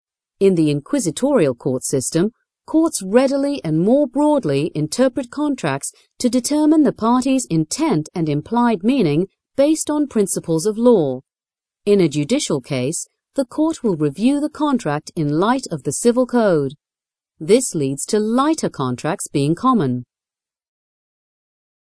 Voice over talent English (British), native speaker. Nonaccent voice. International global vibe.
Sprechprobe: eLearning (Muttersprache):